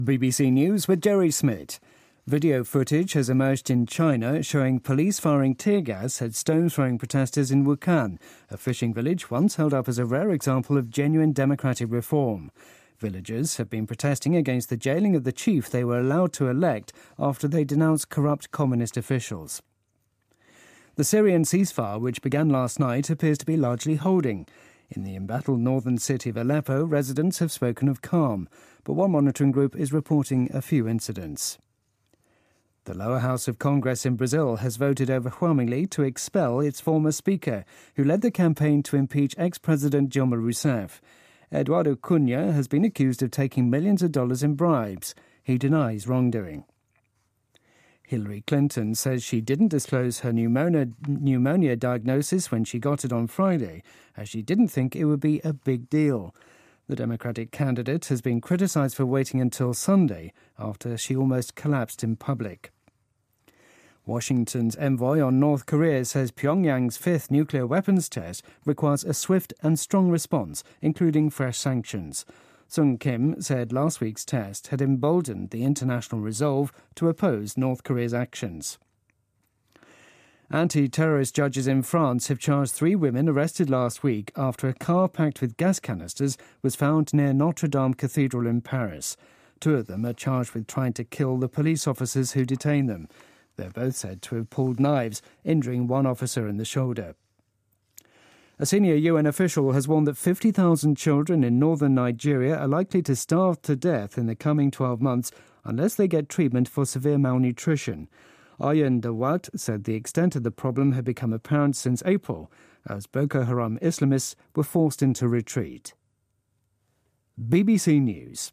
BBC news,朝鲜进行第五次核试验